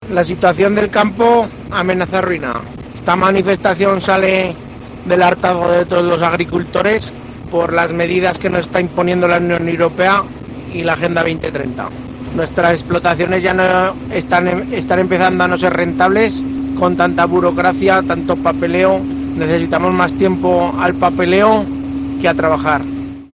agricultor que está participando en las tractoradas de Aragón, muestra en COPE su malestar